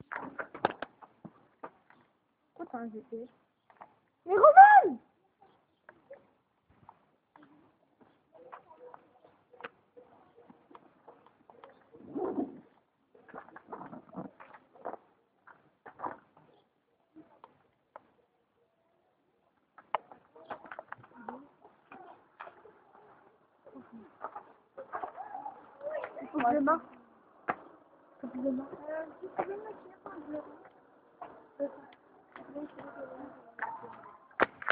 Mairie de Rilhac
Bruits de personnes